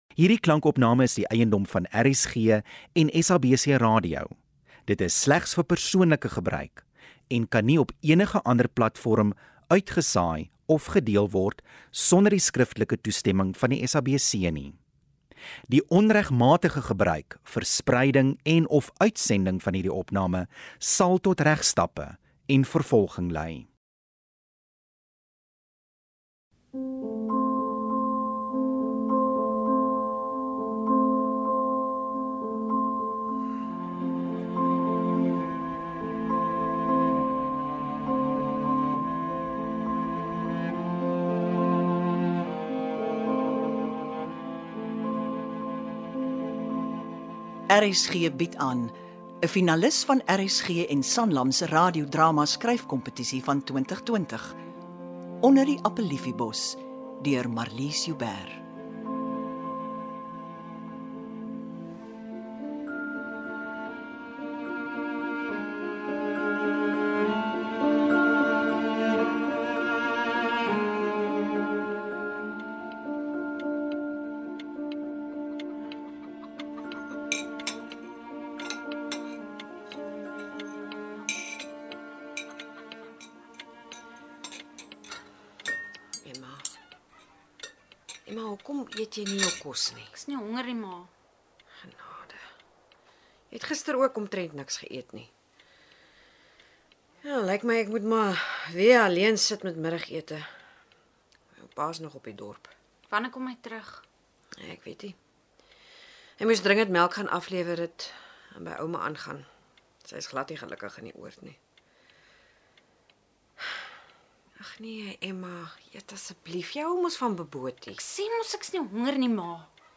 STORIE
Temas soos identiteit, herkoms en vervreemding word aangeraak. Dit is ‘n fyn, sensitiewe drama wat ontroer.
Die spelers het meeste van die byklanke self hanteer.